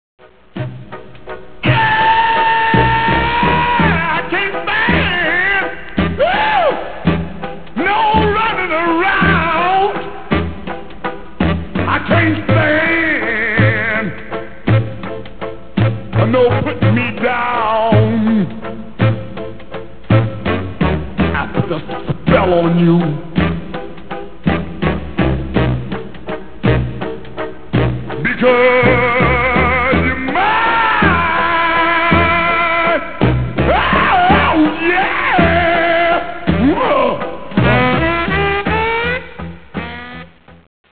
guitare
sax